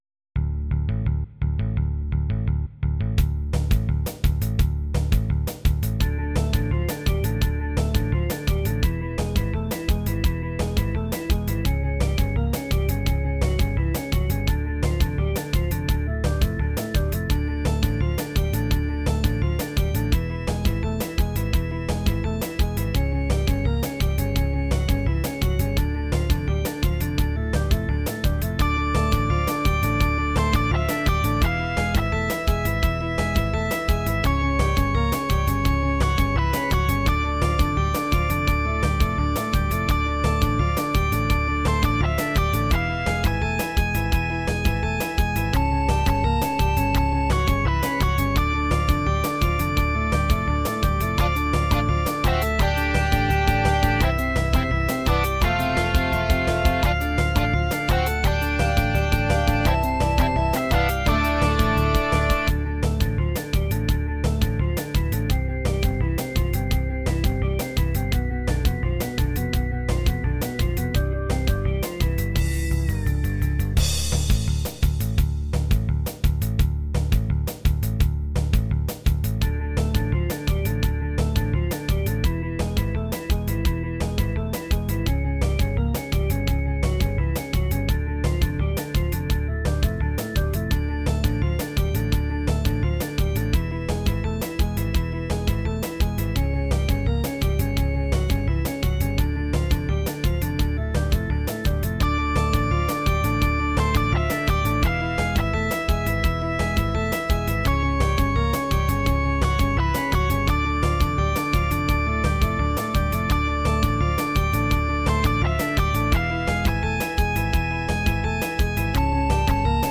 Rendered using Roland SoundCanvas VA in Reaper.